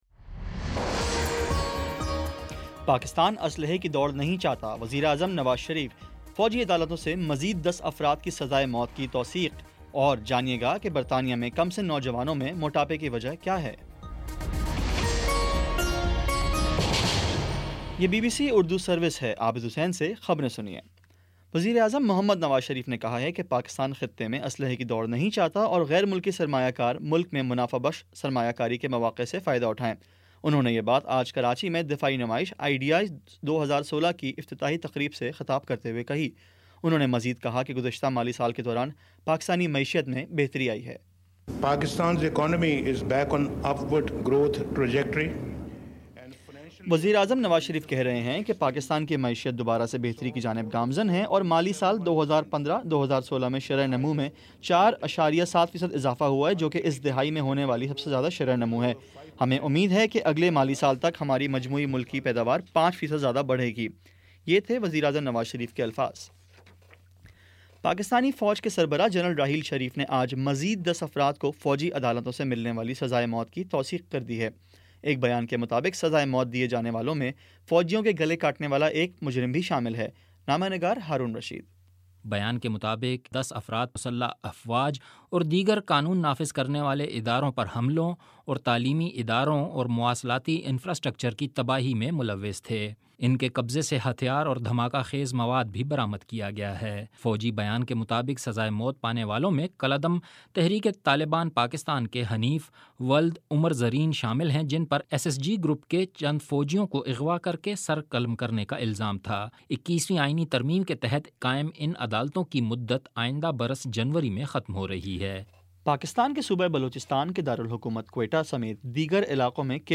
نومبر 22 : شام چھ بجے کا نیوز بُلیٹن